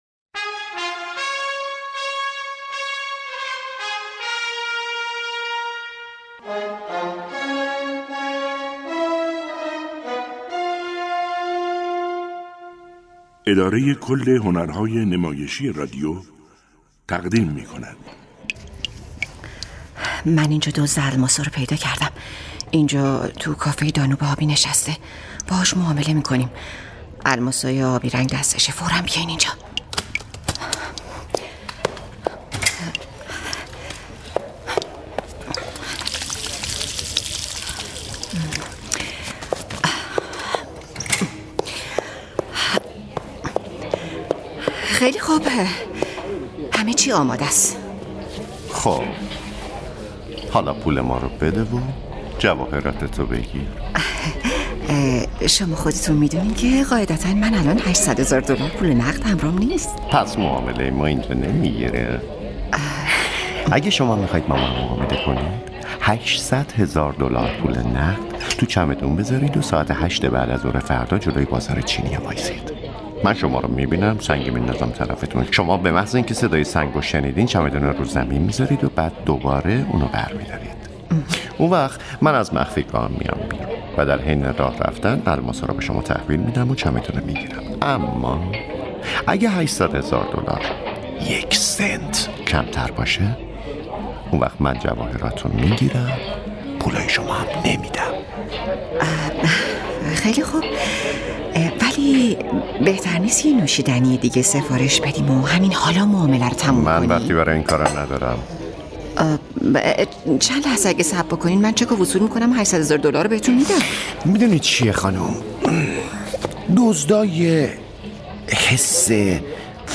مجموعه نمایش رادیویی «پرونده‌های جانی دالر»